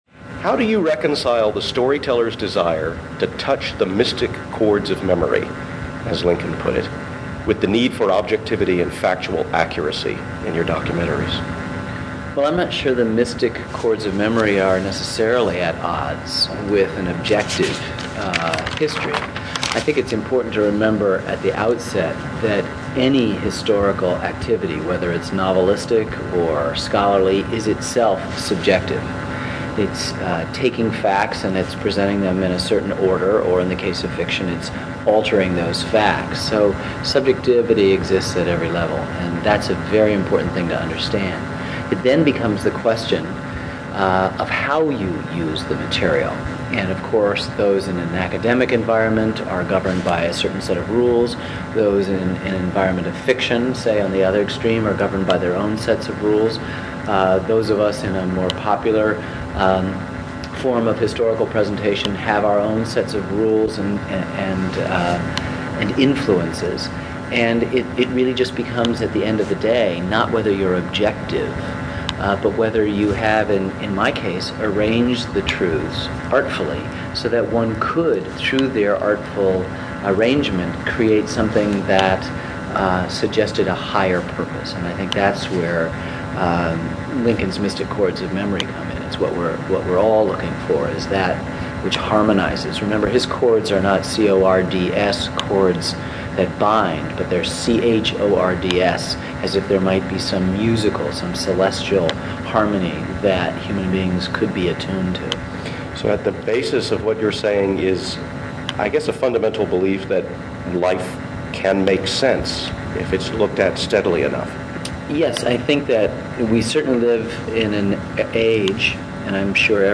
The audio isn’t top-notch: I was using a small cassette recorder and its built-in microphone. There’s also an annoying “click” every so often. But I think the results are at least listenable, and worth hearing for the particular emphasis and tempo of Burns’ responses.
ken_burns_interview.mp3